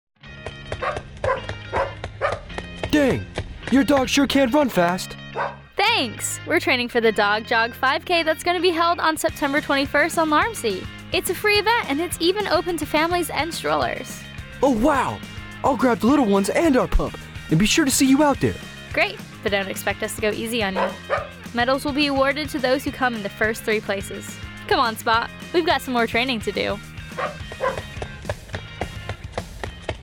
Radio Spot- Dog Jog 2024